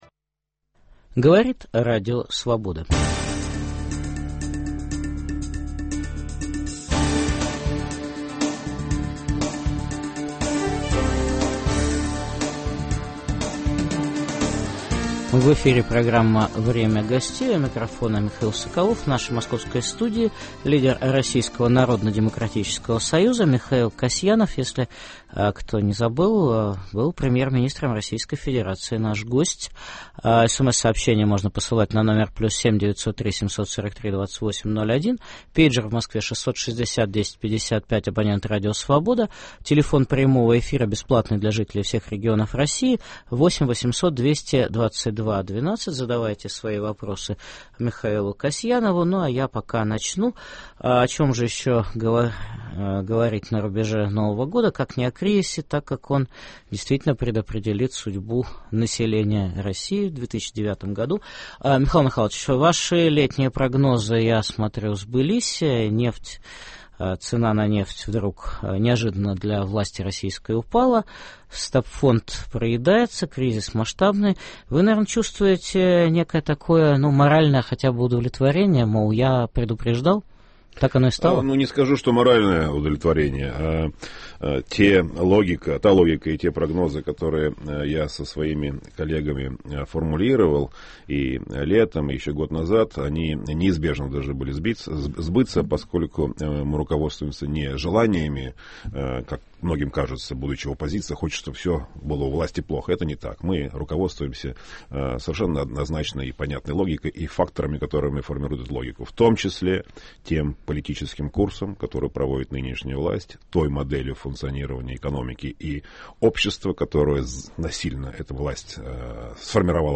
Итоги года подведет лидер Народно-демократического союза Михаил Касьянов.